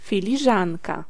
Ääntäminen
IPA: [kɔp]